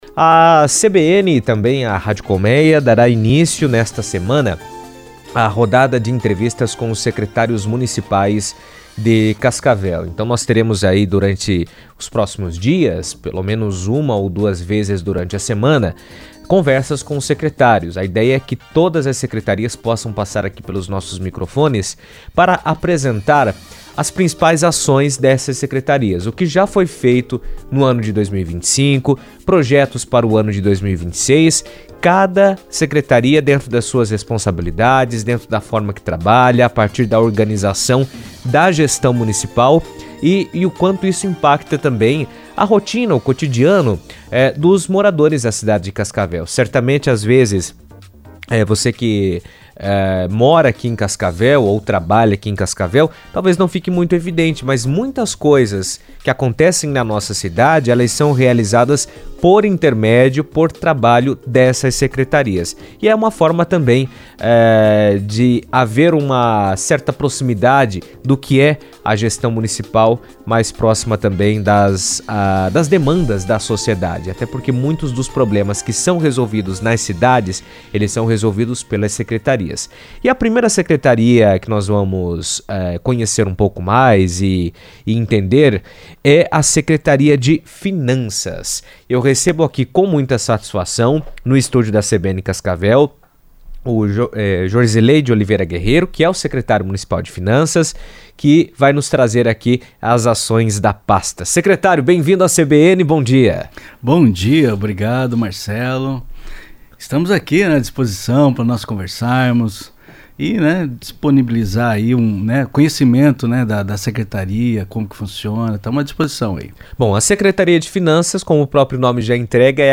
A Secretaria Municipal de Finanças de Cascavel tem papel central na administração da cidade, cuidando da arrecadação de tributos como ISSQN, IPTU e ITBI, além de gerenciar dívidas, convênios e o controle interno das contas públicas. Jorsilei de Oliveira Guerrero, secretário municipal de Finanças, falou sobre o tema na CBN, destacando a importância da gestão eficiente e transparente dos recursos para garantir serviços de qualidade à população.